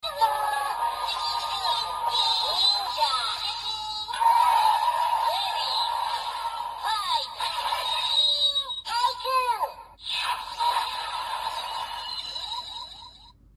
太狸饱藏音效.MP3